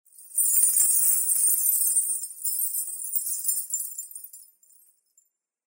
Danza árabe, ruido de monedas de un pañuelo de una bailarina 01
continuo
moneda
Sonidos: Acciones humanas